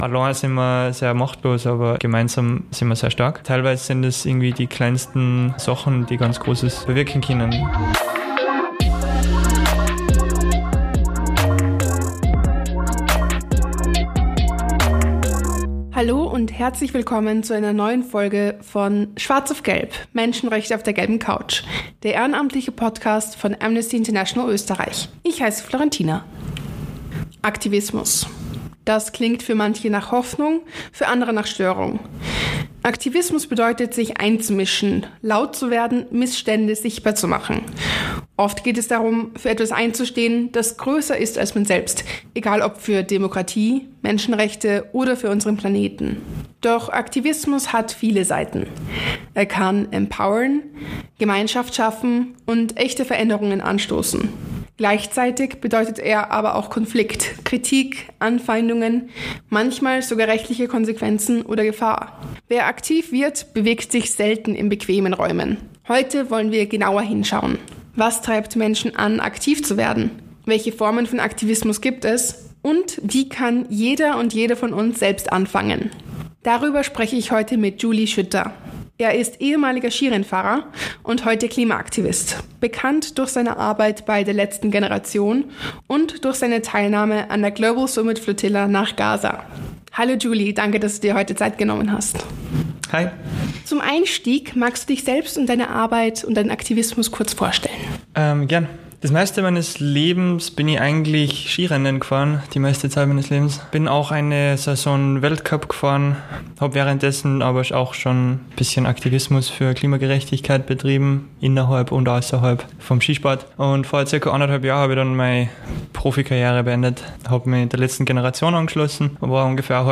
Schwarz auf Gelb – Staffel 2 / Folge 8: Wer, wenn nicht wir? Ein Gespräch